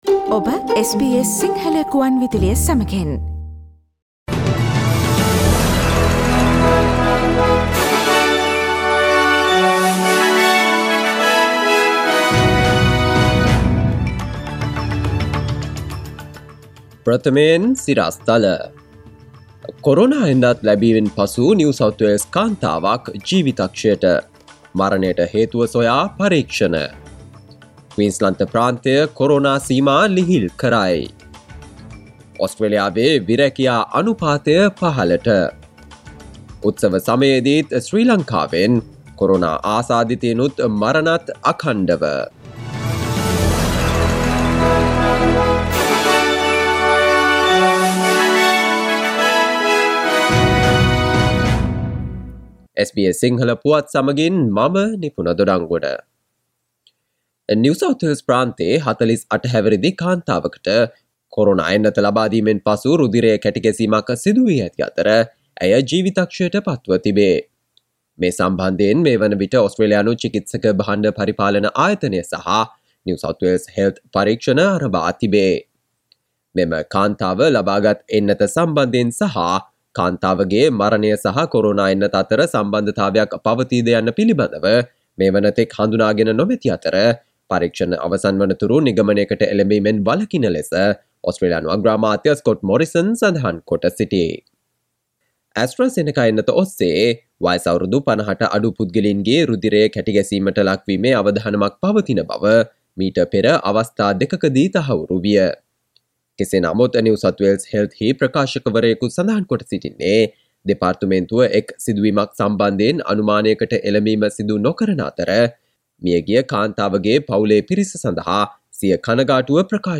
කොරෝනා එන්නත ලැබීමෙන් පසු නිව් සවුත්වේල්ස් ප්‍රාන්ත කාන්තාවක් ජීවිතක්ෂයට : අප්‍රේල් 16 දා SBS සිංහල ප්‍රවෘත්ති ප්‍රකාශය